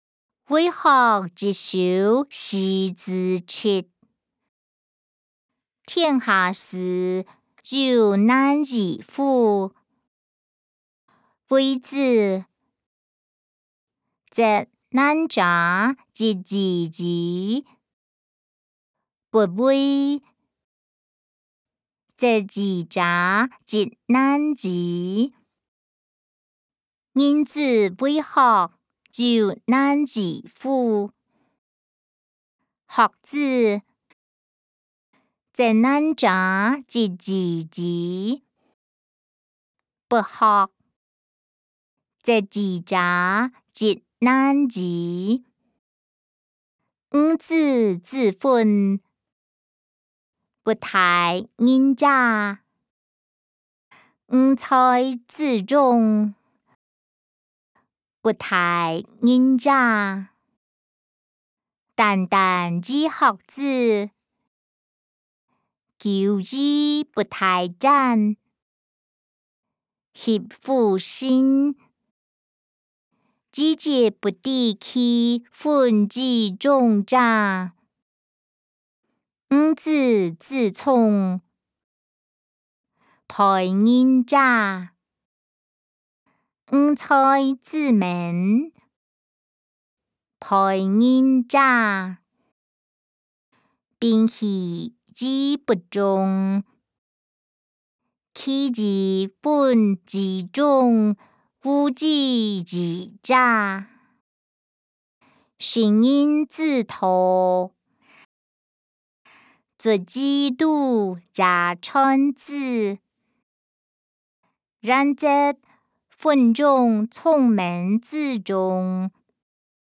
歷代散文-為學一首示子姪音檔(海陸腔)